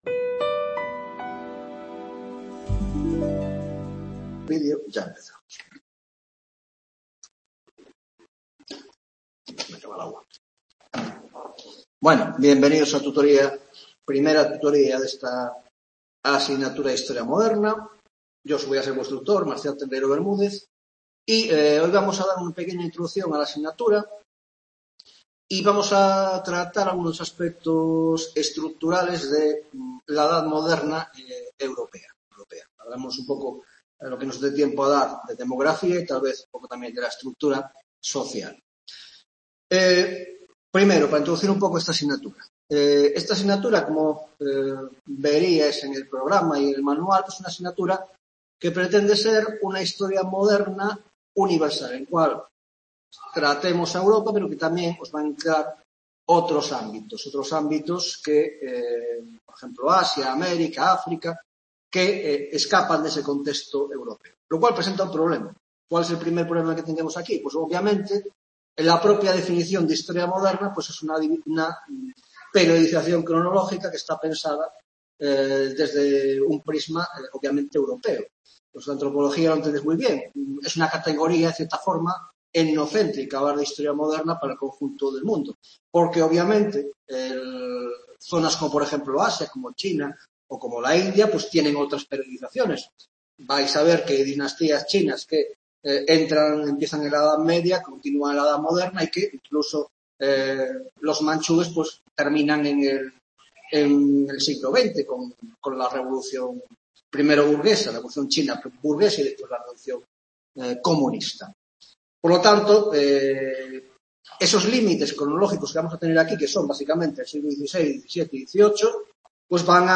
1ª Tutoria Historia Moderna (Grado de Antropologia Social y Cultural): 1) Introducción general a la asignatura